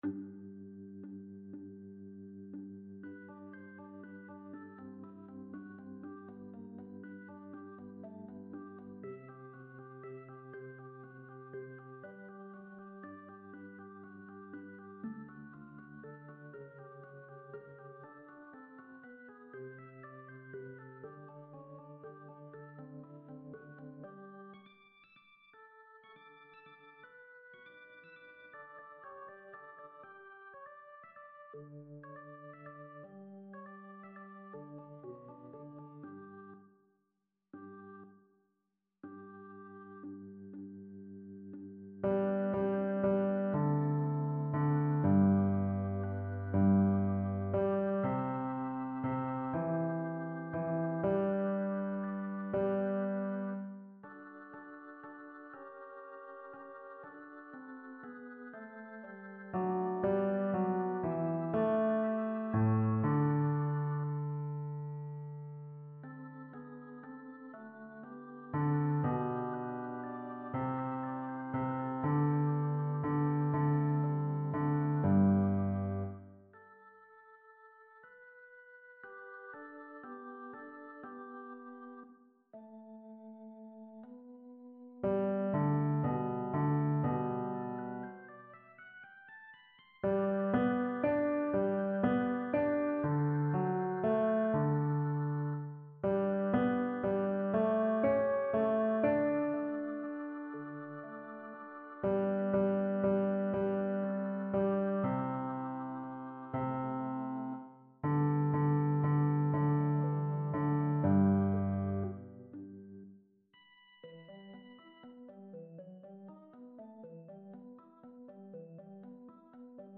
Fichiers de Travail des Basses